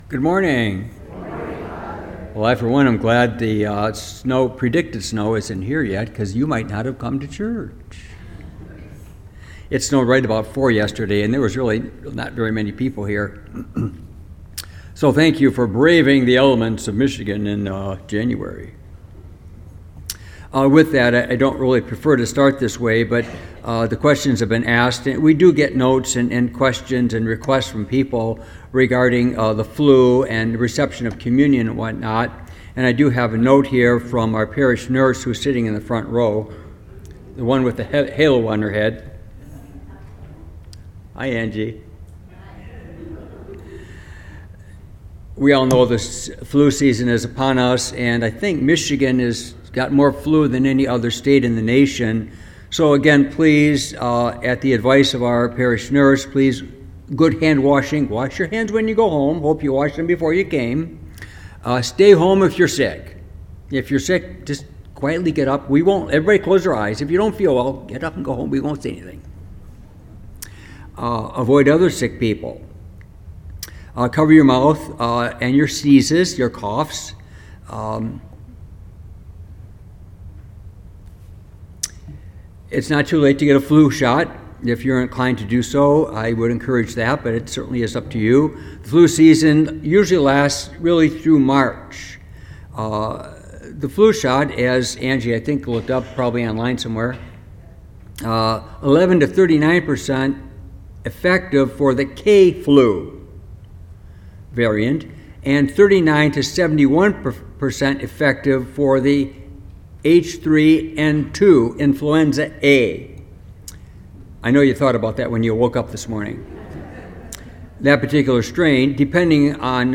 Homily , January 11,2026
Homily-The-Baptism-of-the-Lord.mp3